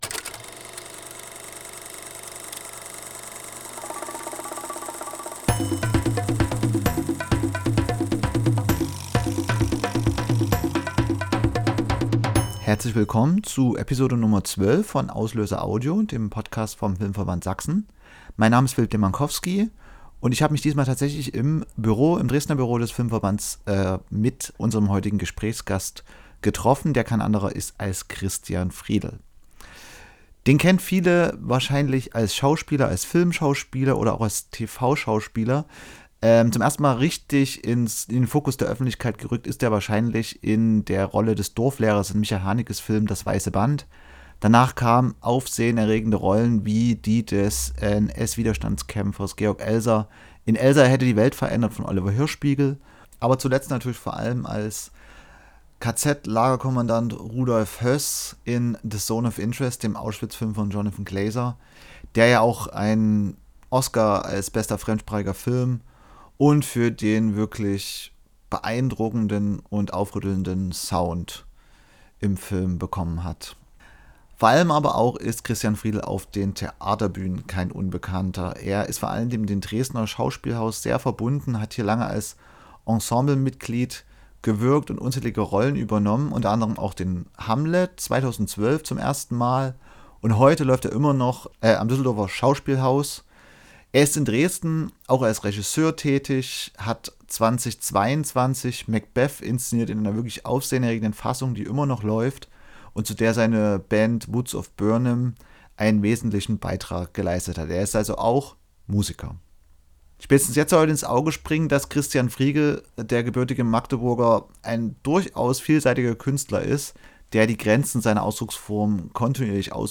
Beim Interview im Büro des Filmverbands Sachsen gibt Christian Friedel Einblicke in seine Arbeit in den verschiedenen Disziplinen, die Bedeutung seiner Wahlheimat Dresden und die Herausforderungen und Möglichkeiten der deutschen Filmbranche.